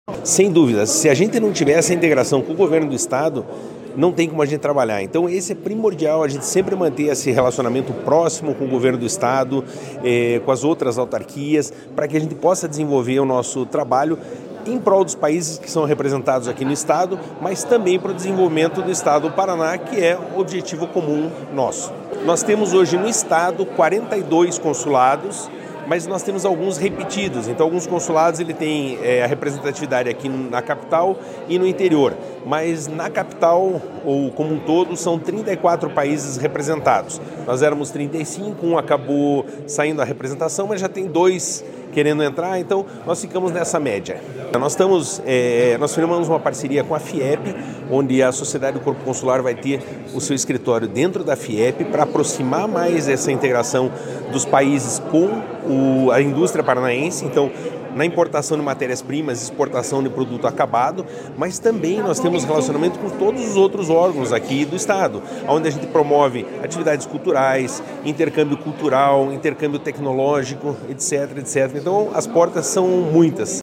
Sonora do presidente do Corpo Consular